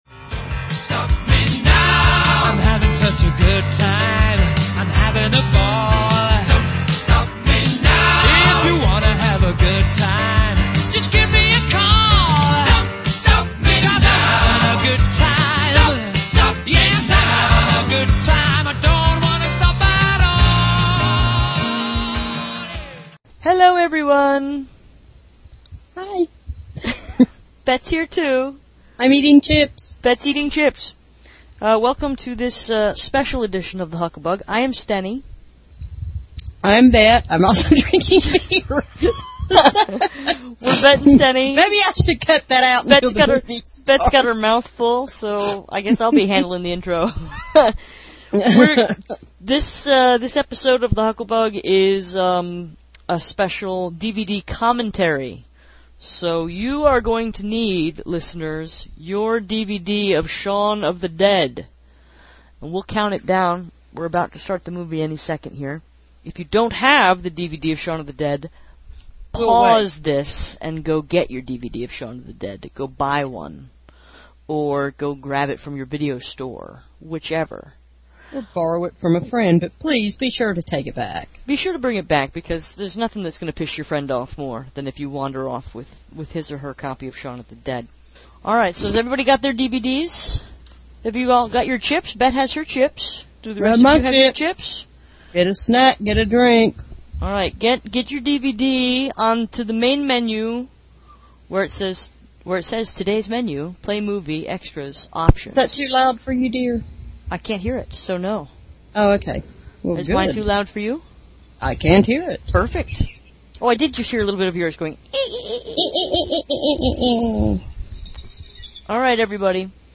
This week’s episode is a DVD commentary for the Edgar Wright/Simon Pegg film Shaun of the Dead , sometimes called the Official Movie of the Hucklebug.